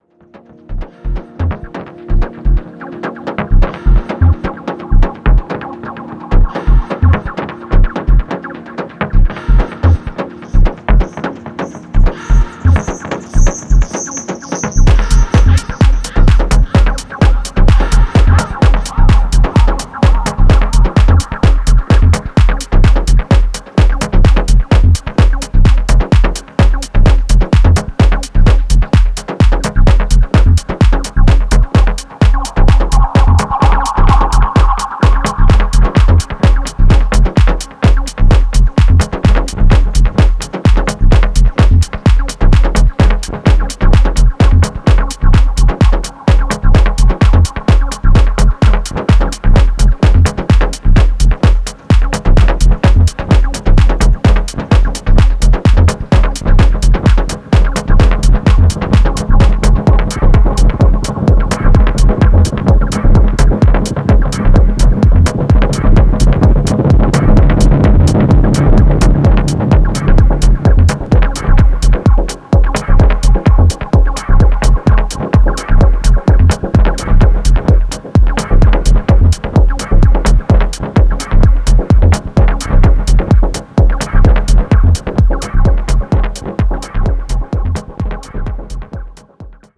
躍動感漲るシンセとパンピンなビートが奏でるミニマルグルーヴが素晴らしい。